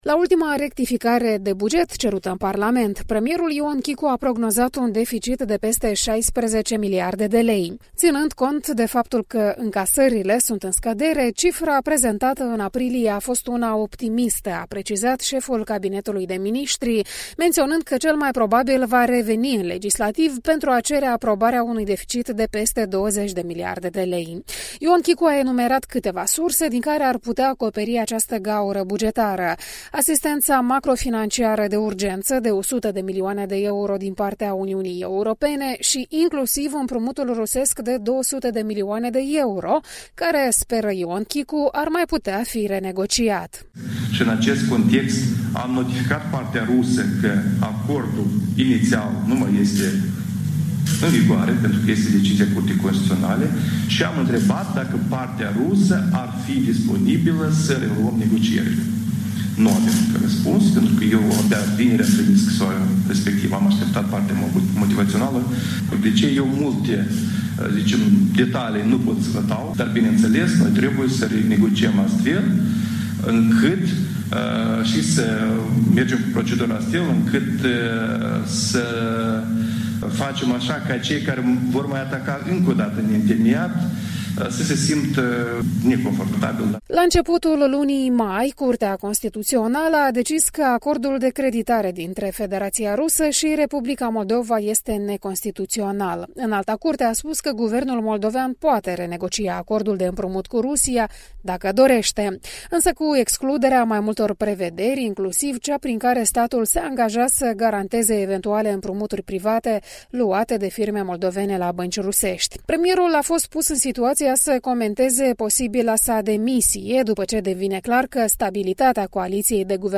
Odată cu încheierea stării de urgență, premierul Ion Chicu a revenit la modelul clasic de organizare a conferințelor de presă, cu participarea jurnaliștilor.